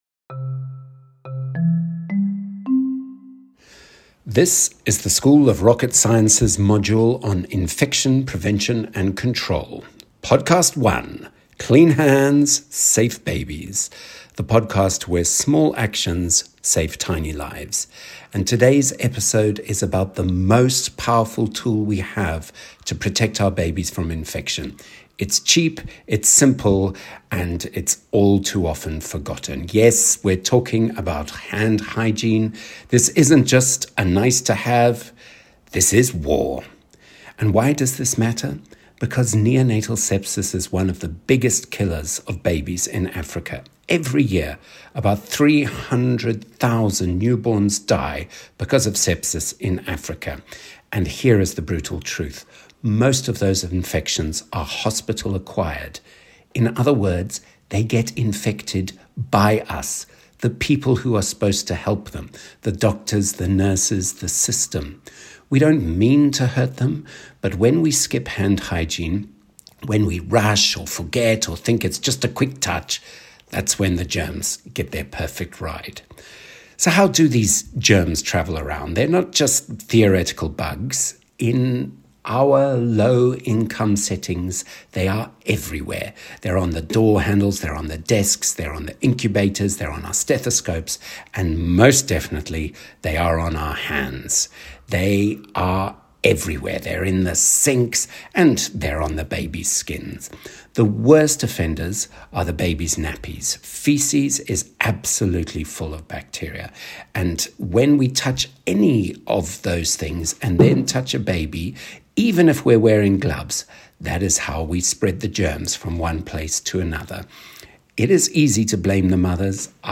Hand Hygiene Lecture Audio